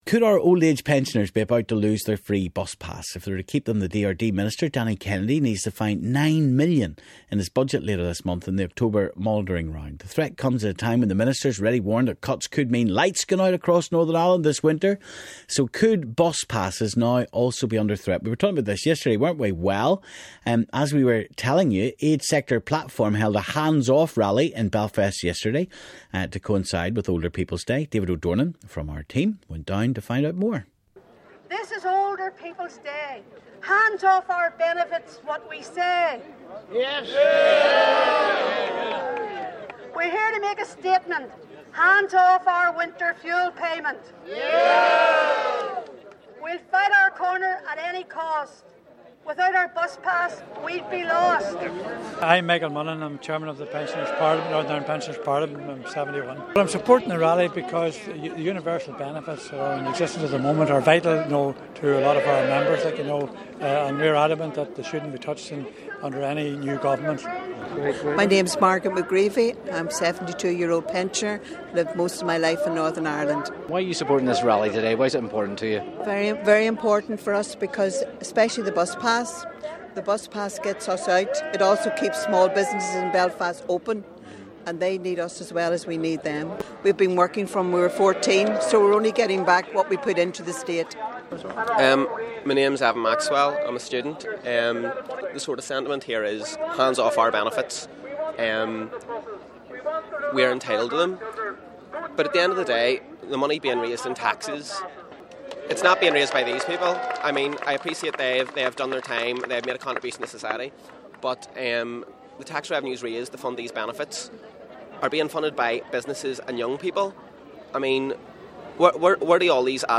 Age Sector Platform held a 'Hands Off' rally in Belfast yesterday to coincide with Older People's Day.